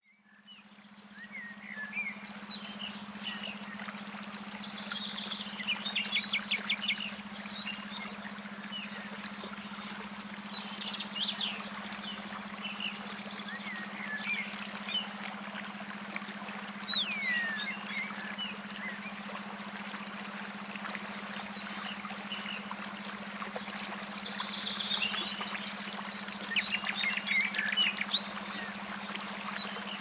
This audio contains various types of natural background sounds. The background sounds will create a vivid natural environment & you will get a virtual journey inside of nature.
At first you are in a place where many birds are around you singing and a small river is flowing in front of you. After some time strong wind strong wind starts to flow, thunder starts to strike and the birds are gone. A few minutes later it starts to rain and you have taken a shelter nearby enjoying the relaxing sound of rain.
After a few minutes of raining it stops. After the rain has stopped you have come out from the shelter & the birds again starts coming to the place. You hear them singing around you again.
Types of Entrainment Sounds: Isochronic Beat
Background Sounds/Environment: Sound of birds, sound of small river & fountains, sound of thunder, sound of wind, sound of rain and sound of rain on leaves
Frequency Level: Alpha